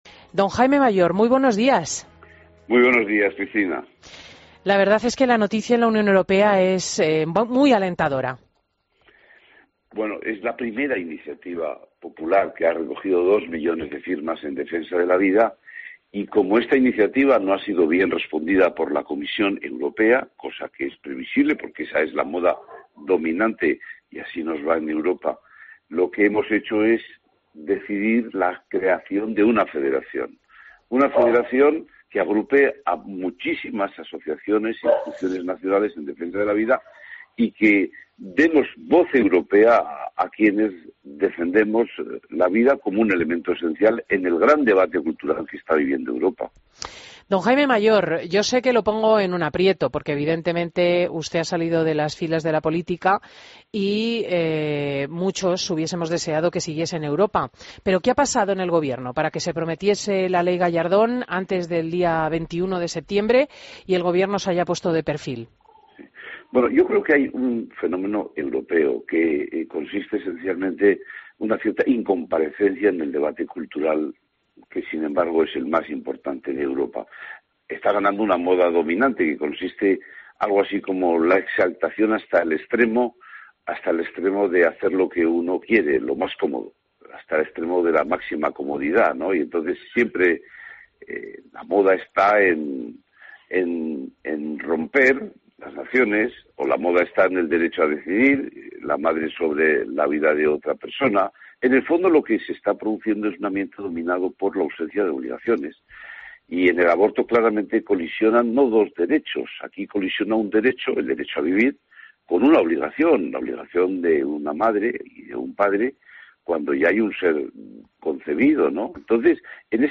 Entrevista a Jaime Mayor Oreja en Fin de Semana COPE